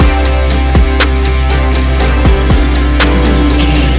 Ionian/Major
C♭
ambient
electronic
chill out
downtempo
synth
pads